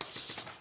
paper_flip_longer.ogg